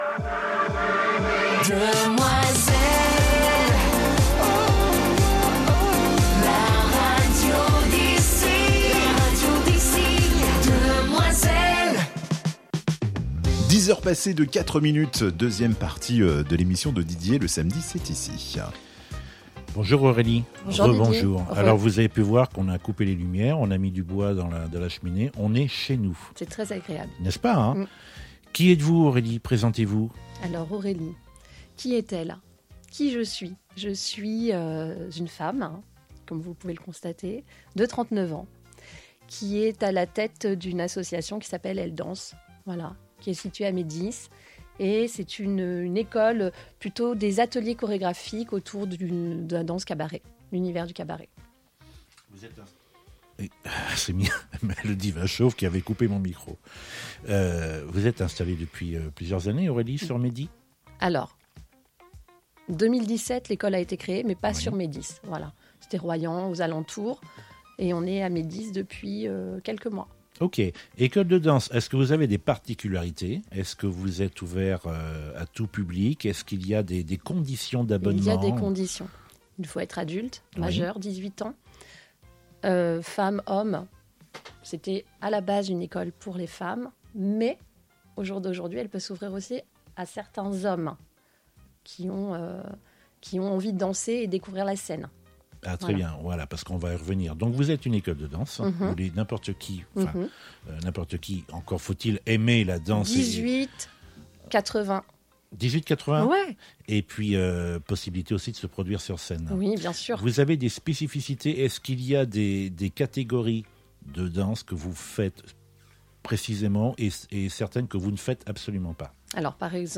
Dans cette interview, retour sur son parcours, ses projets, ses inspirations.